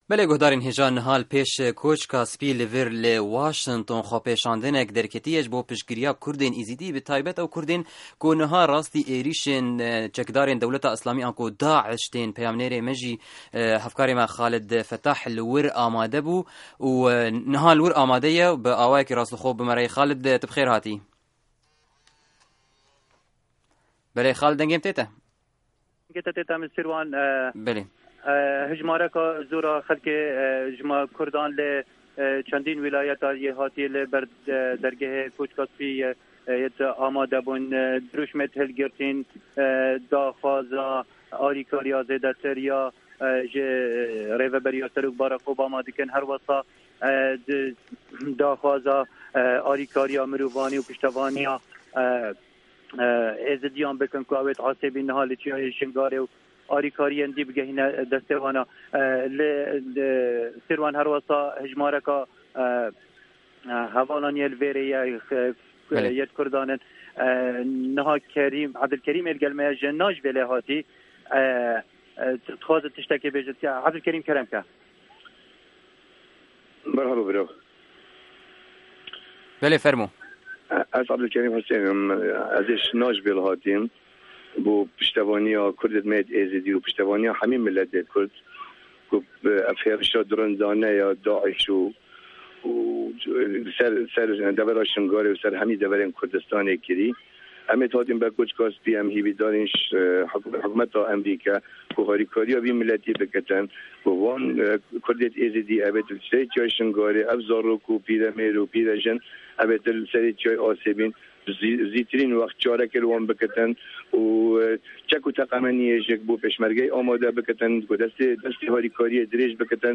Bêtir zanîn di vê raporta dengî de ye.